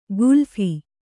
♪ gulphi